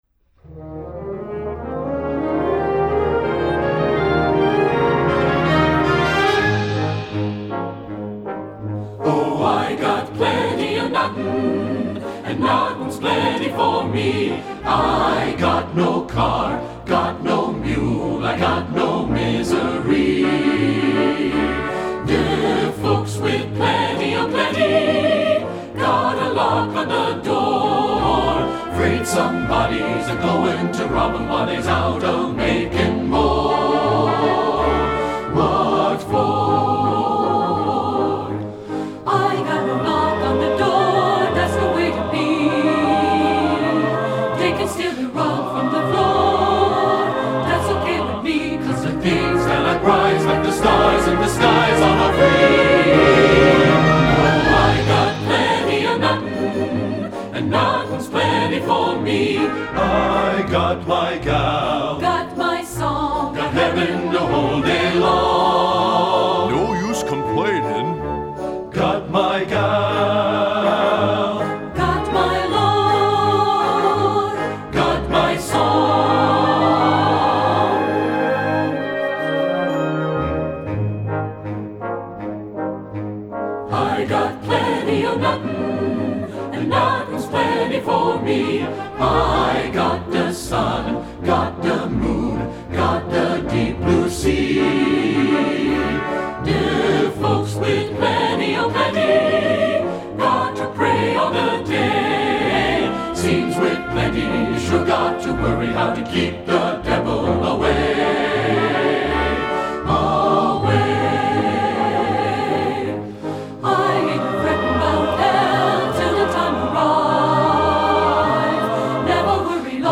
Voicing: TBB